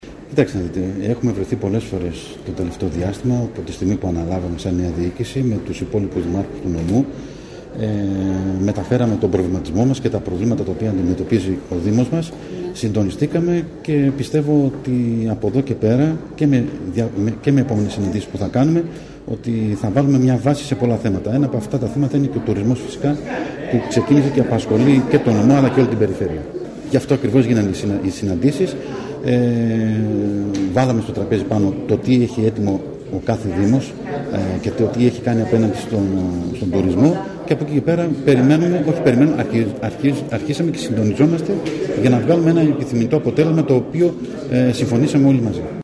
Ταμπουρίδης Λευτέρης – Δήμαρχος Νευροκοπίου